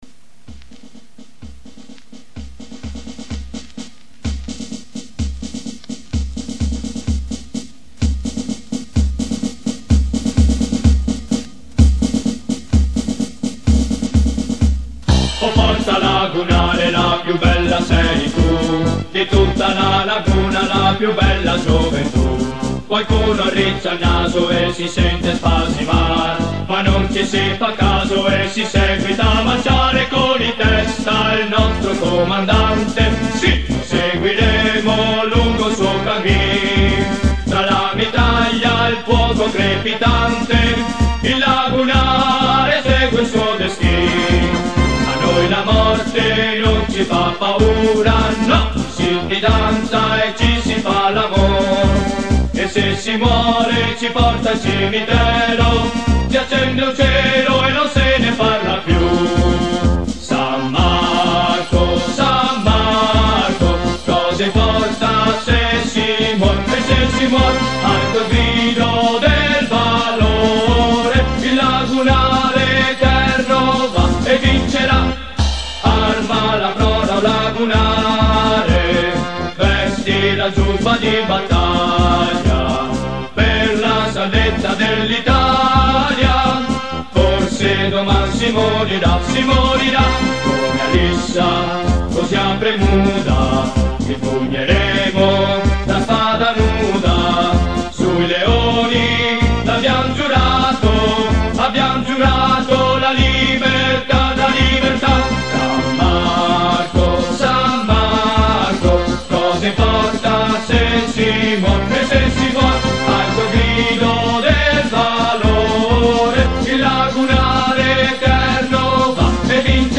inno_dei_lagunari_coro_originale.mp3